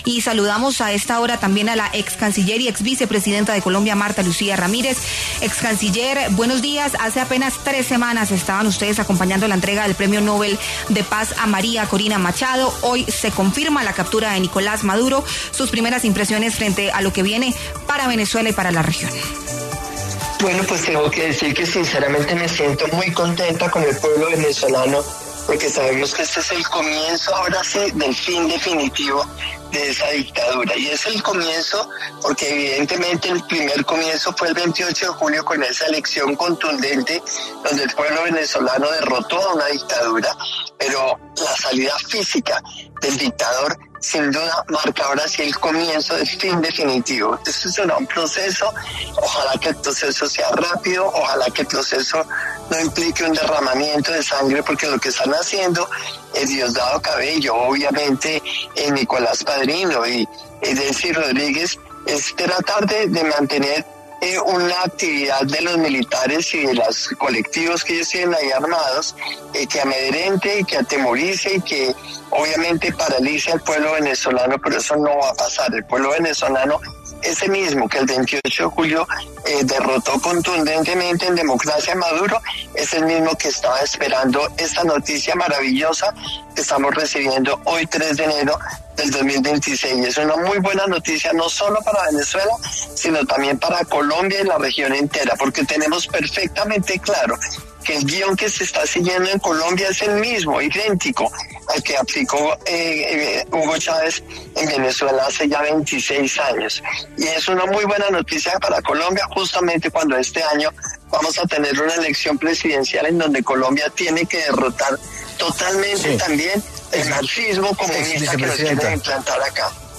La ex vicepresidenta de Colombia, Marta Lucía Ramírez, conversó con La W a propósito del anuncio que hizo Donald Trump sobre la captura de Nicolás Maduro y los ataques en territorio venezolano.